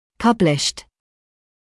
[‘pʌblɪʃt][‘паблишт]опубликованный, изданный